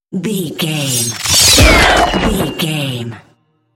Whoosh metal sword creature
Sound Effects
dark
intense
whoosh